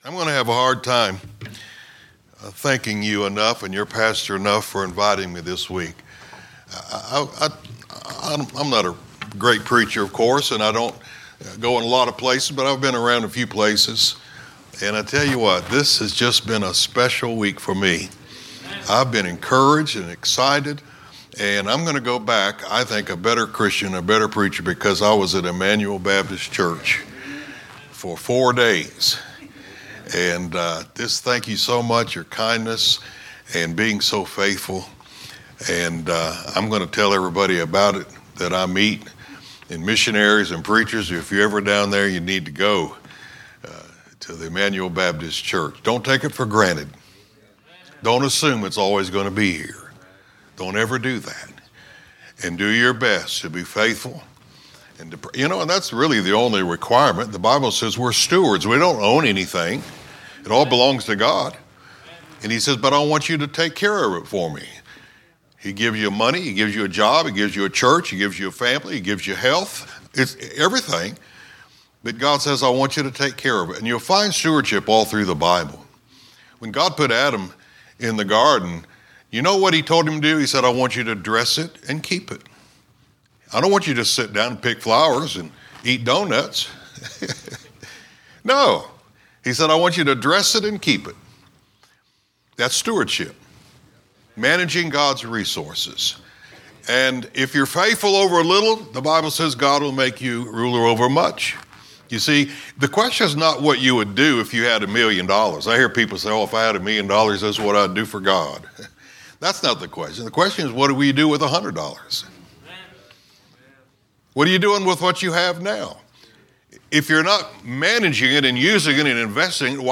" Missions Revival " Sermons preached during our annual missions revival